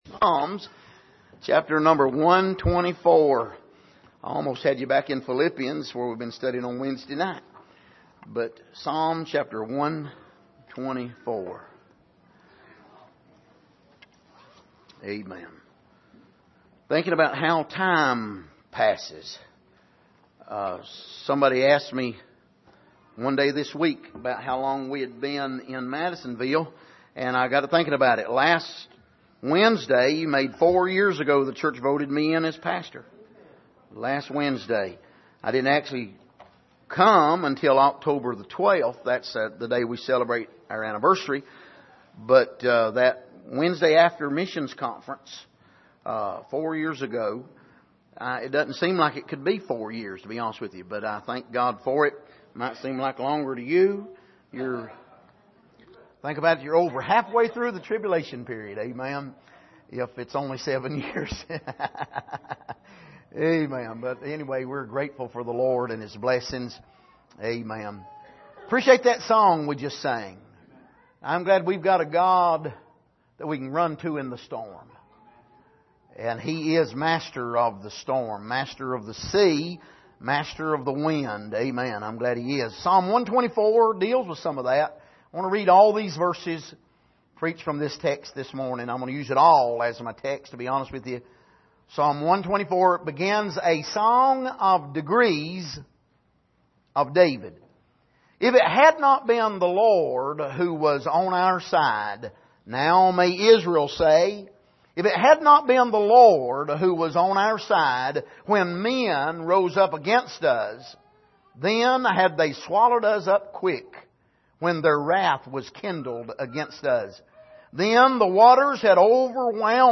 Passage: Psalm 124:1-8 Service: Sunday Morning